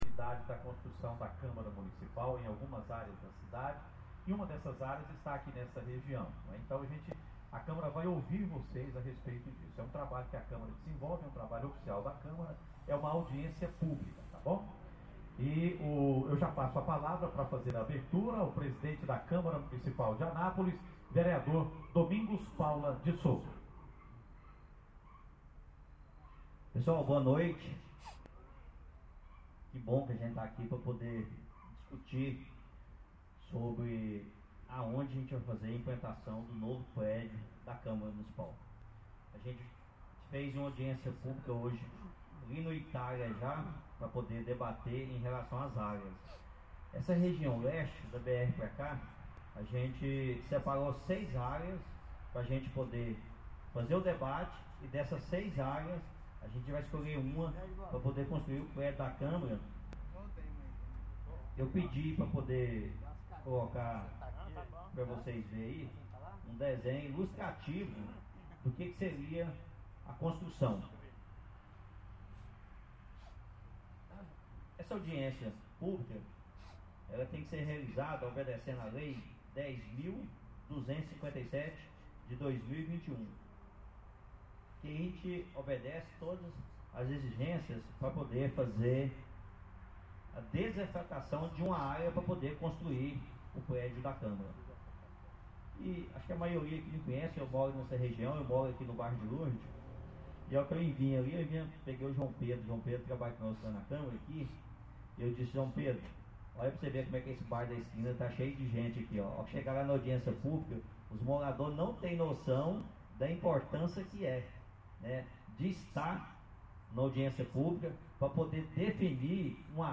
Audiência plub. para discussão da proposta de área para construção da sede própria do poder legislativo. Bairro Jardim Palmares Dia 12/05/23.